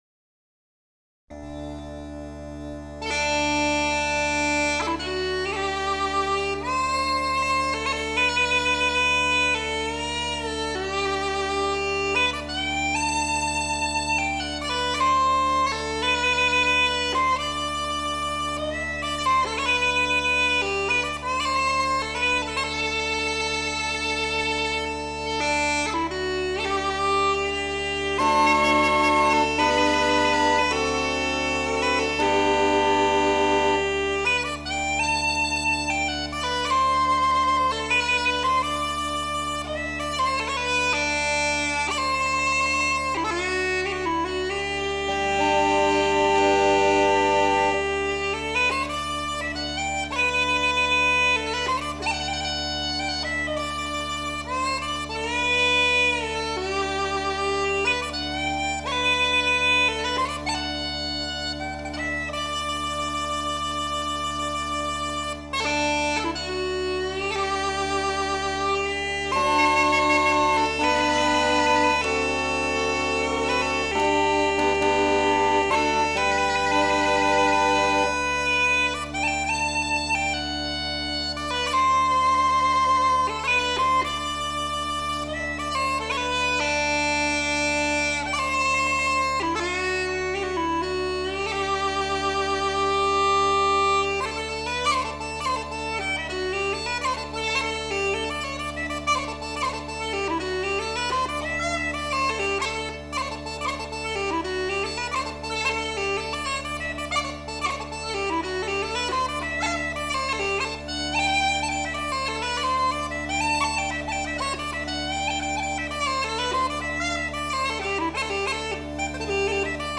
Traditional musician from Co. Down, Northern Ireland.
playing Uilleann pipes solo